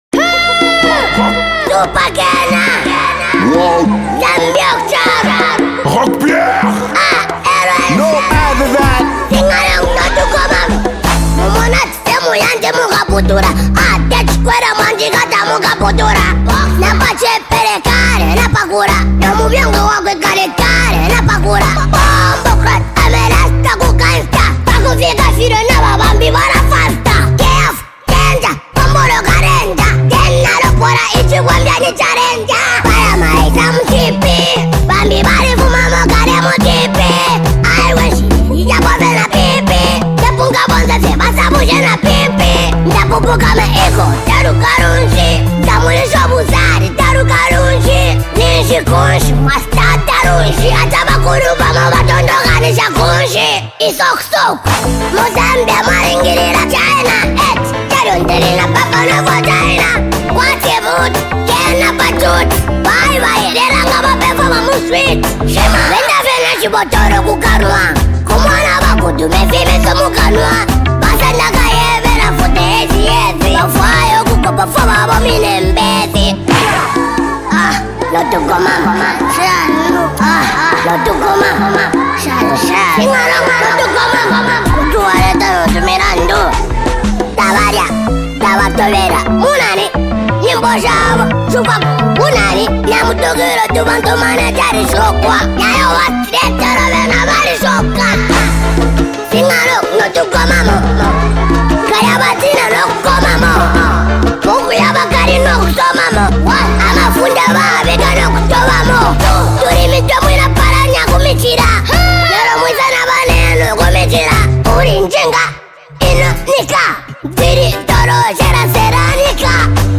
" captures a vibe that’s both raw and inspiring